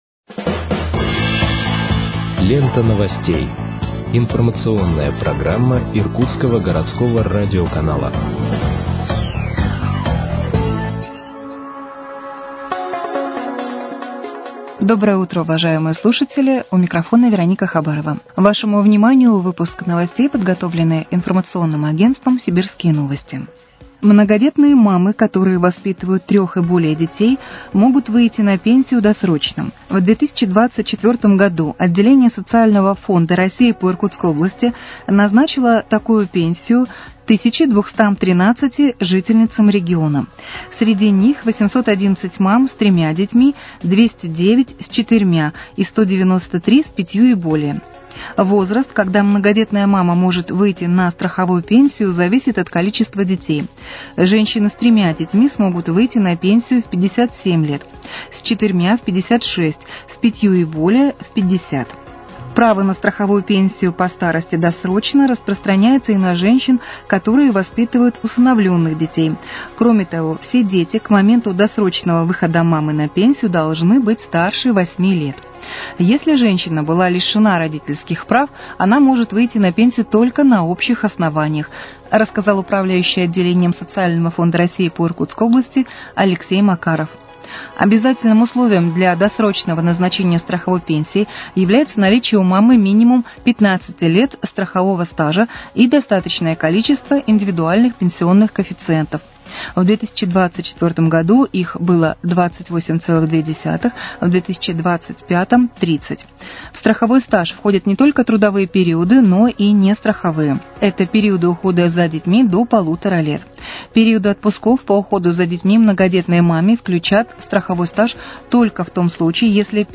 Выпуск новостей в подкастах газеты «Иркутск» от 10.03.2025 № 1